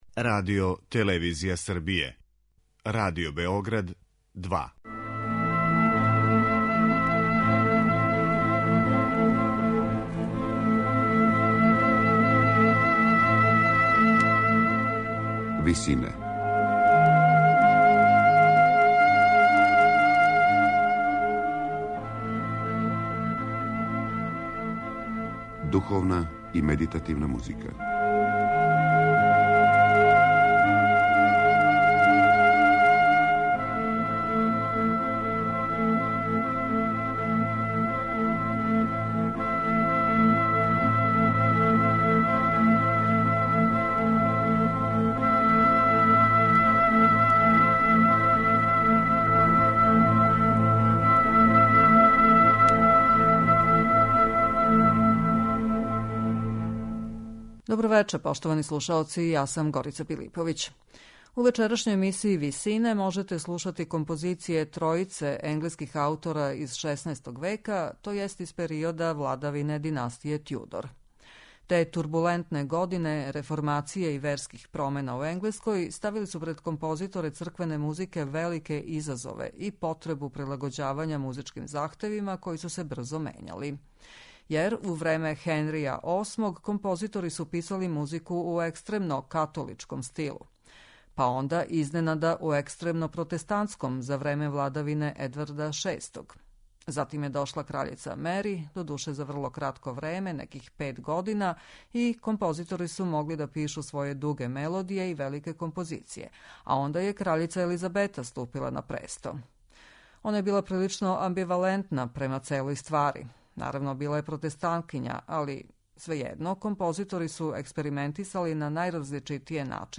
Ренесансни композитори !6. века
медитативне и духовне композиције
У емисије Висине можете слушати композиције енглеских ренесансних композитора из XVI века - Џона Шеперда, Вилијама Мандија и Ричарда Дејвија.